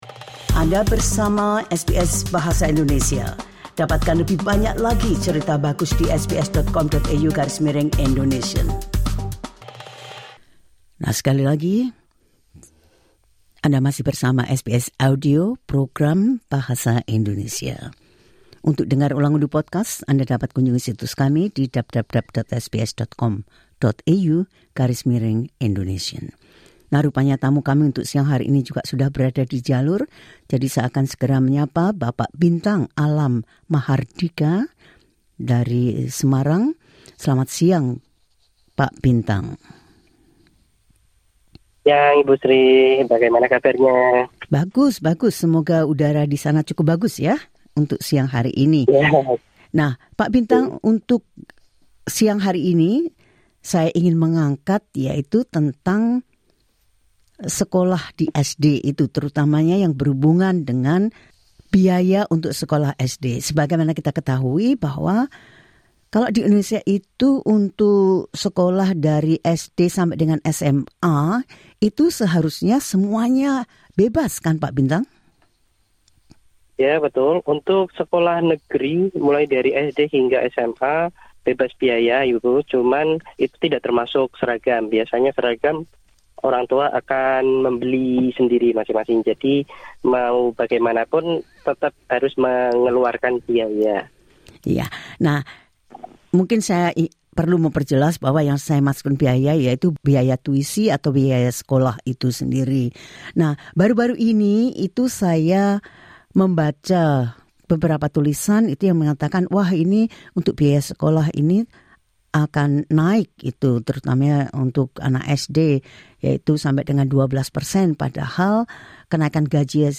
LISTEN TO Kenaikan biaya Sekolah Dasar 12,6% dapat membebani orang tua murid yang kurang mampu SBS Indonesian 16:07 Indonesian SBS Indonesian berbicara dengan salah satu orang tua murid di Semarang